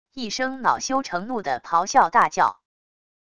一声恼羞成怒的咆哮大叫wav音频